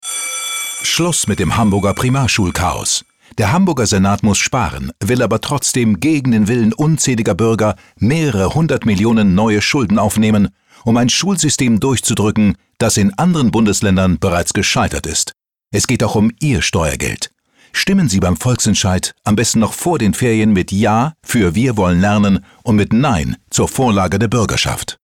Radiospot